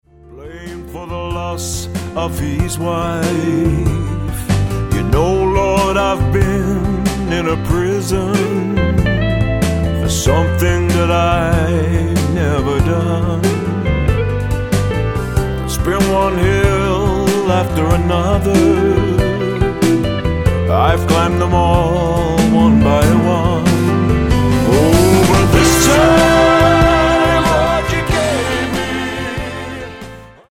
--> MP3 Demo abspielen...
Tonart:C Multifile (kein Sofortdownload.